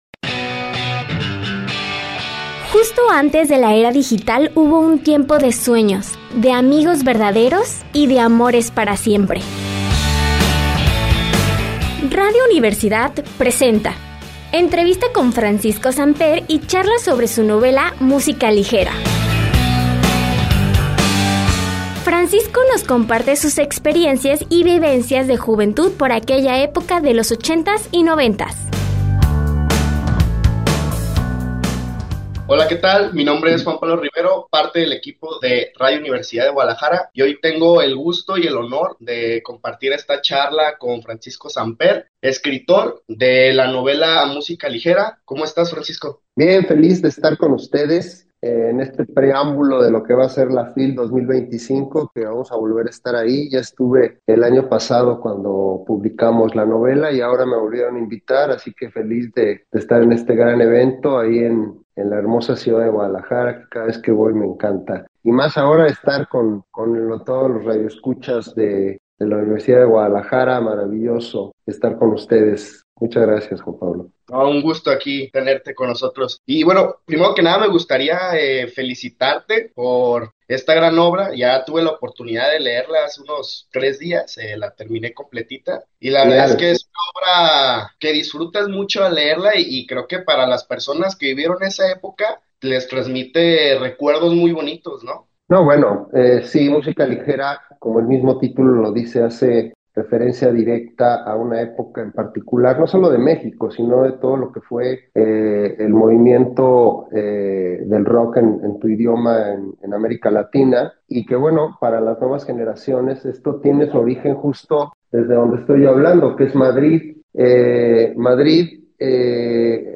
Cobertura Fil 2025 - Entrevista